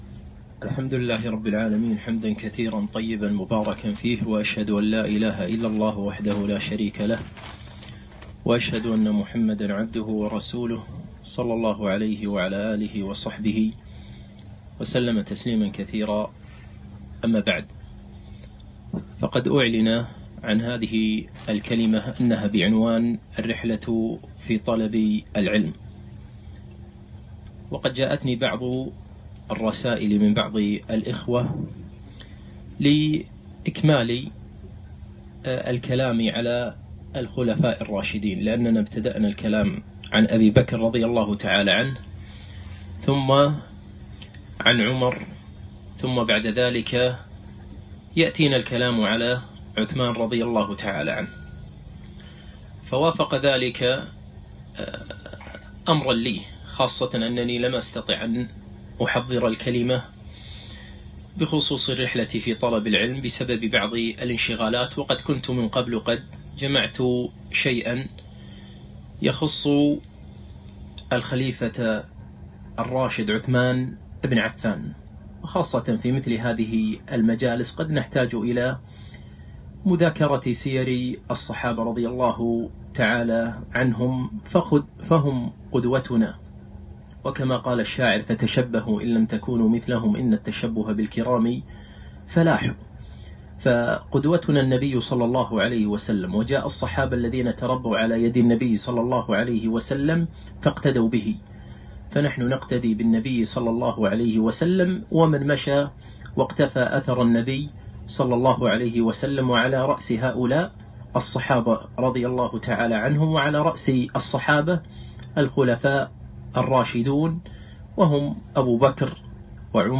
محاضرة - الصحابي عثمان بن عفان رضي الله عنه - دروس الكويت